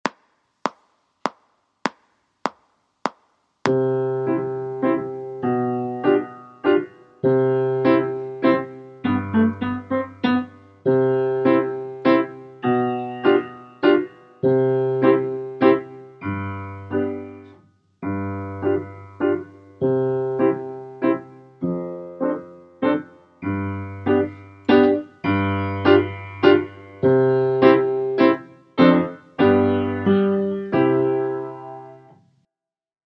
Mock Trumpet C Clarinet Piano Only